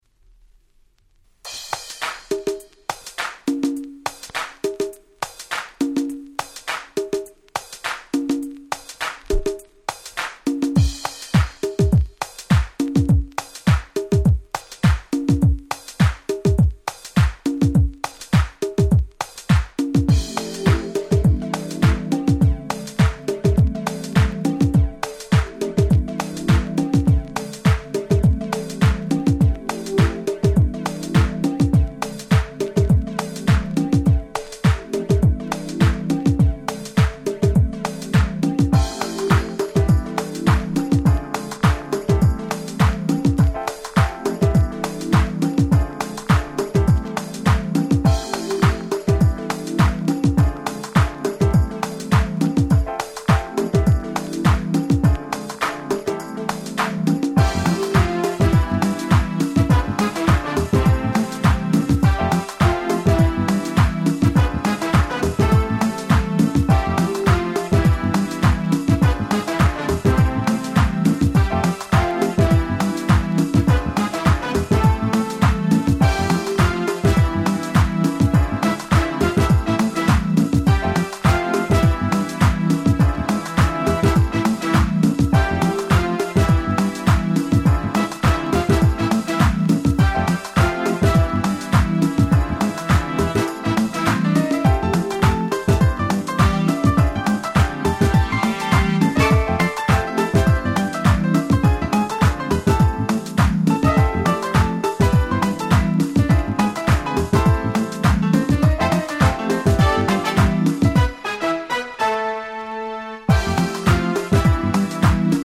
JazzyなBreak Beats物やGround Beatがお好きな方には間違いなくドンピシャなはず！！